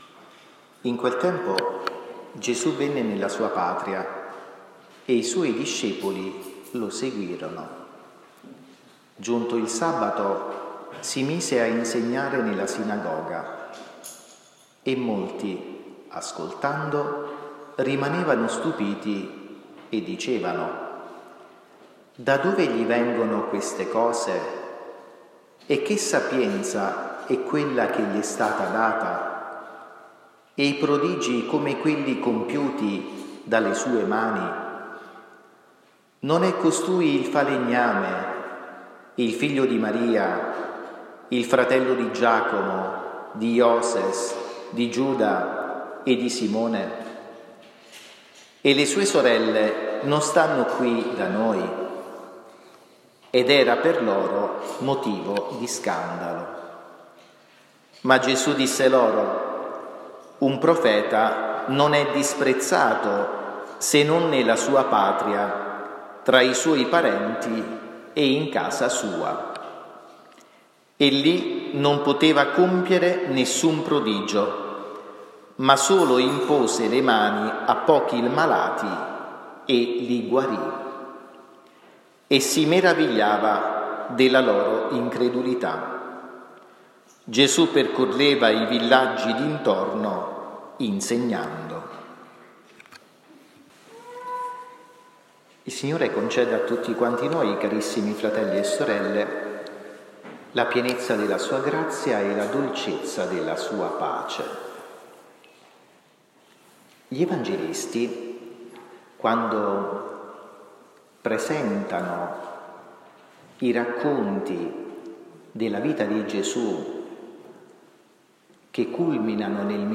omelia-domenica-7-luglio.mp3